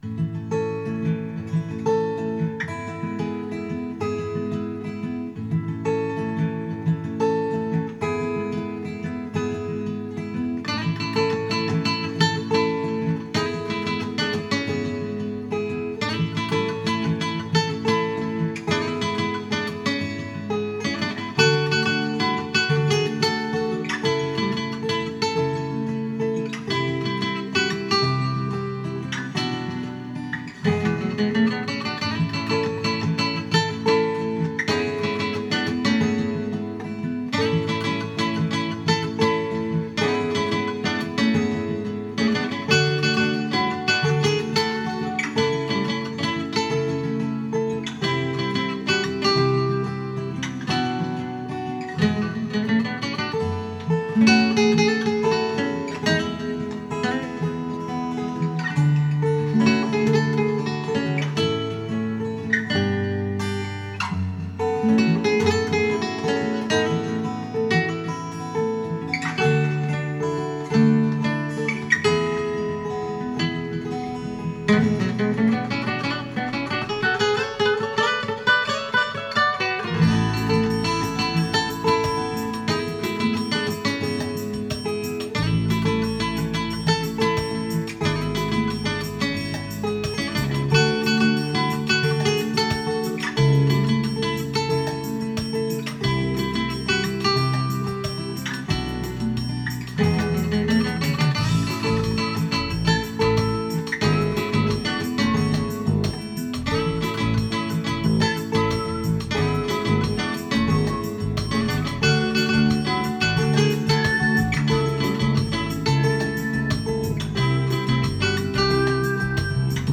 ショットガン・ステレオマイク　CSS-5
CDの音楽をスピーカーから再生し、
DR-100で96kHz24bitと48kHz16bitで録音しました。
スピーカーは、DS-500とYST-SW50です。
CSS-5-96kHz24bit.wav